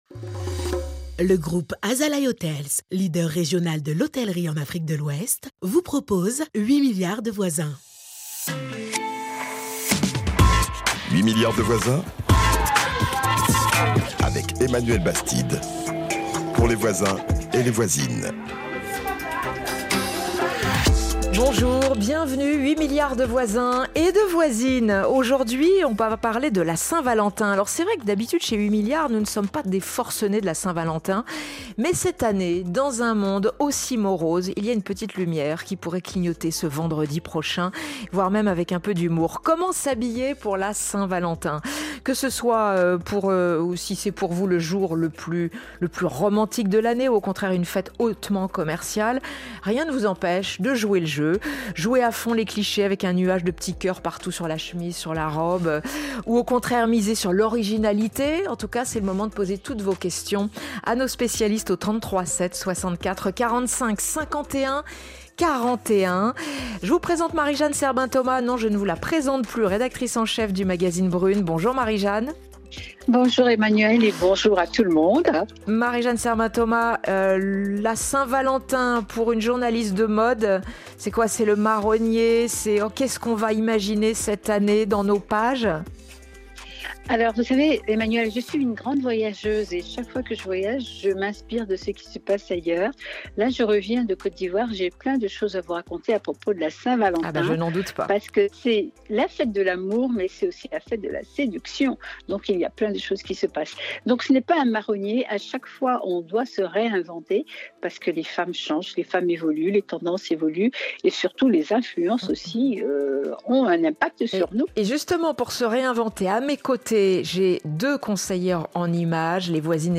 Emploi des jeunes, entrepreneuriat, environnement, place des femmes, éducation, urbanisation: comment évoluent nos modes de vie et nos sociétés dans le monde ? À l’antenne ou en podcast, 8 milliards de voisins donne la parole à tous les habitants de la planète, avec des débats et des conseils pratiques pour réaliser des projets professionnels. 8 milliards